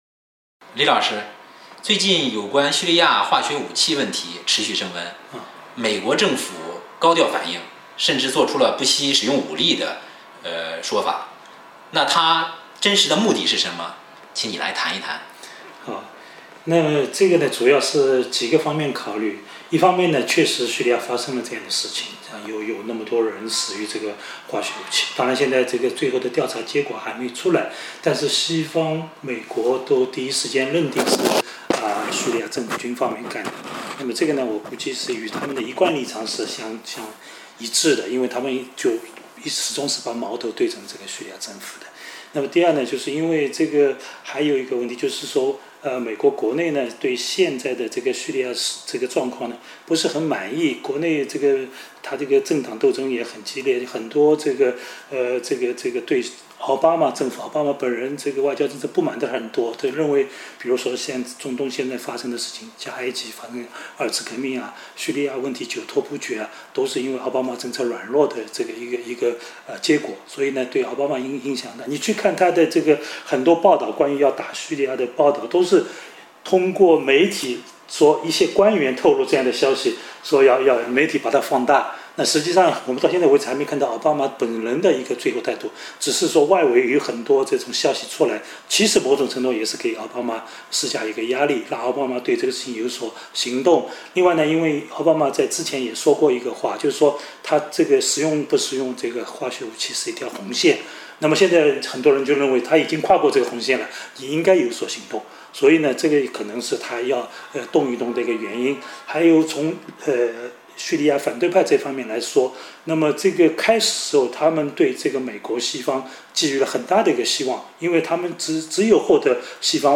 专家访谈：叙利亚战云密布原因何在 2013-08-30 近日，叙利亚问题因使用化学武器导致大量平民伤亡而急剧升温，美英等国跃跃欲试，准备动武，空中轰炸大有一触即发之势，叙利亚事态升级的背景是什么，一旦战端开启将会产生什么后果。